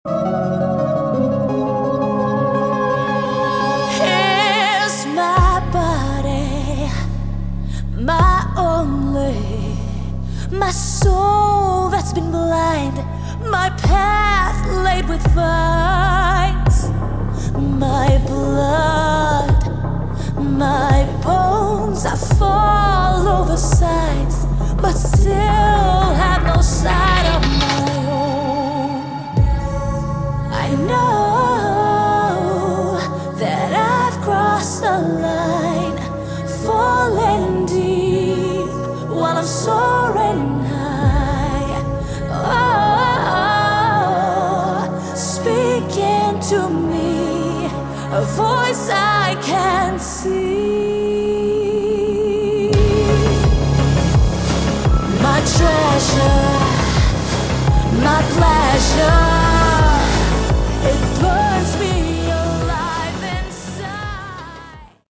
Category: Melodic Metal
vocals
guitars, bass, synths/electronic arrangements
drums